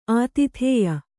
♪ ātithēya